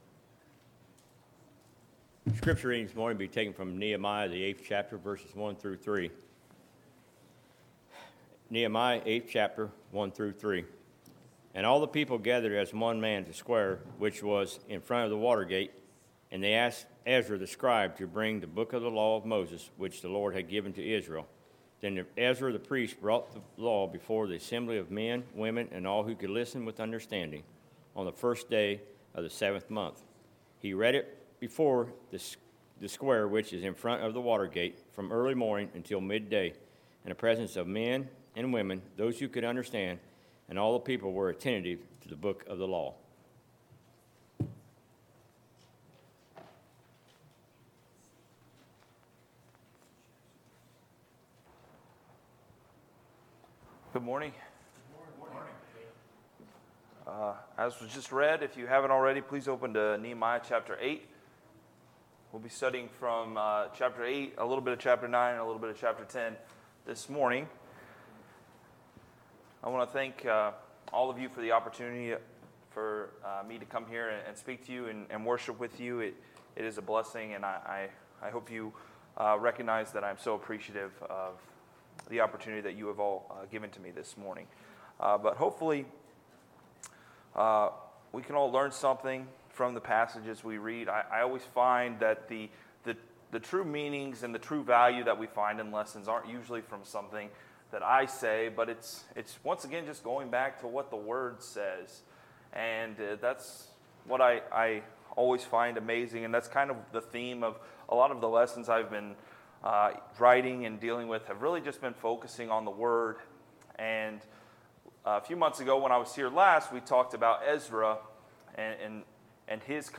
Sermons, January 19, 2020